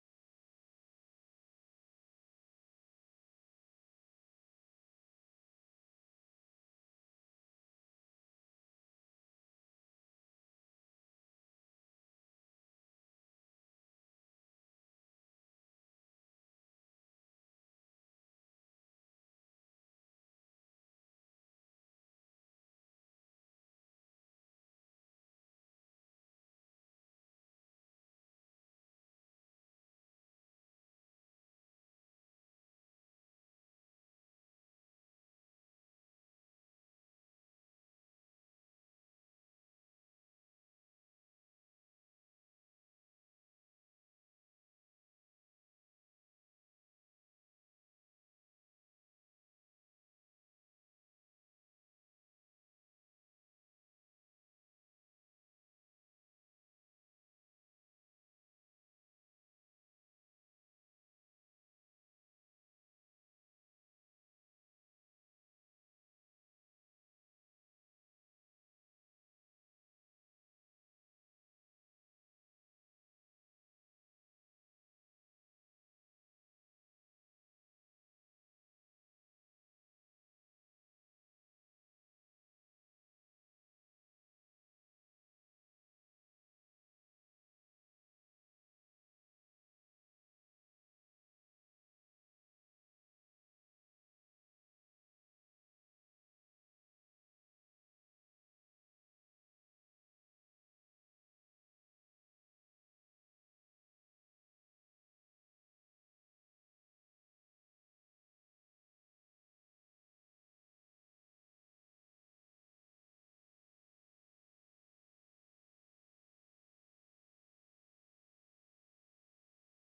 Bilingual Worship Service - 01st January 2023